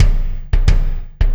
Kick Particle 08.wav